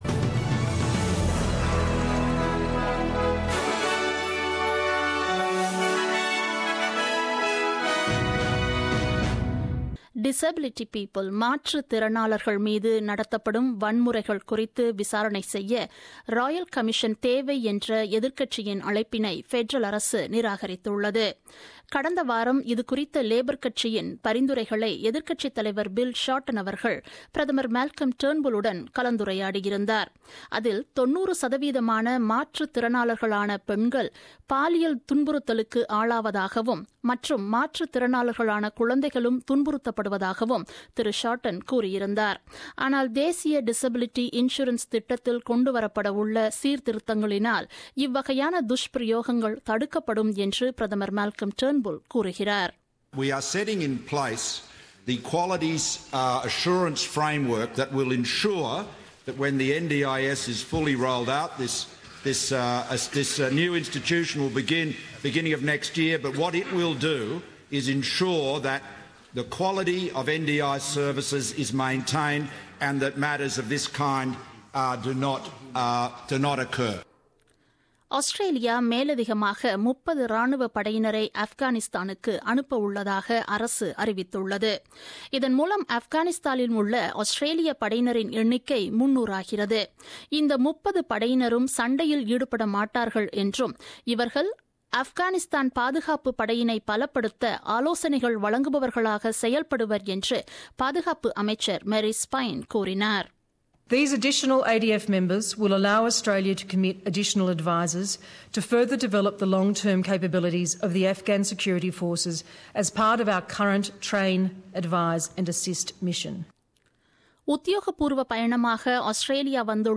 The news bulletin broadcasted on 29 May 2017 at 8pm.